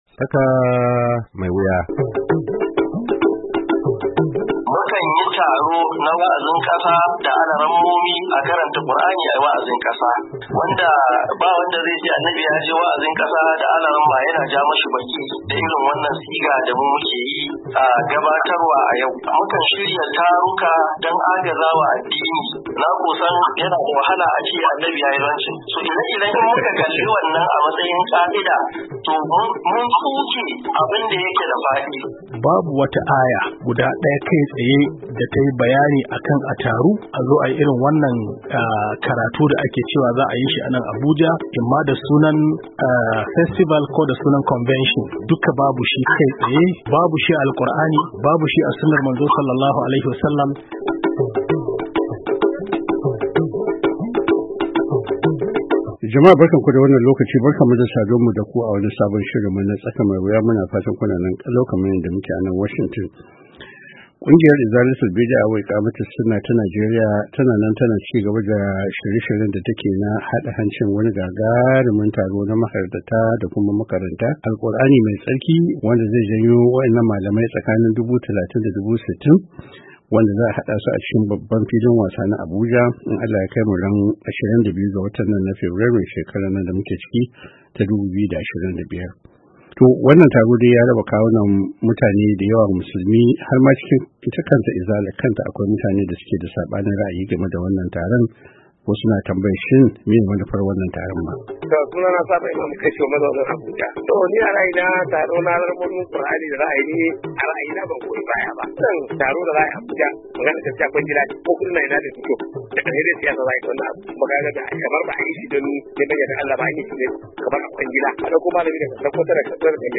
TSAKA MAI WUYA: Tattaunawa Kan Batun Babban Taron Mahaddata Alqur’ani Mai Girma A Abuja Da Kungiyar Izala Ke Shirin Yi Fabrairu 11, 2025